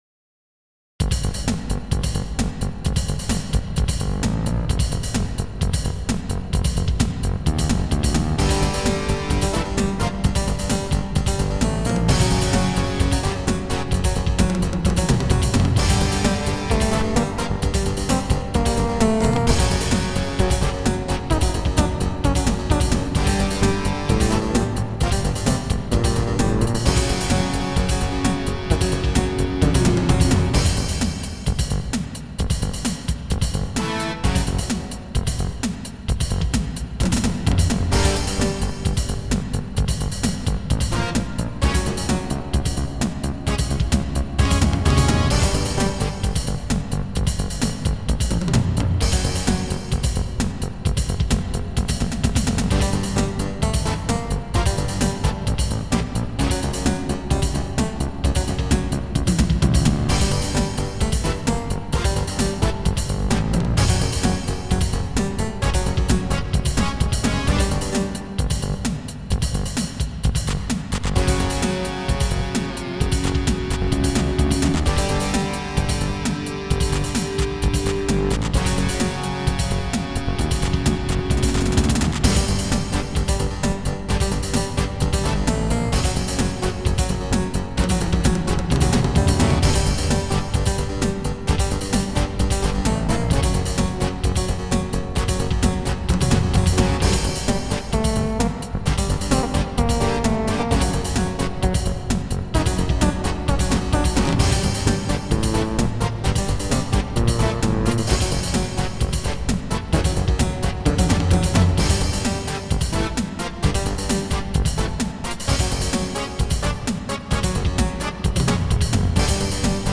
gothic, folk and rock
Recorded digitally, using a Sound Blaster Audigy 2 SE.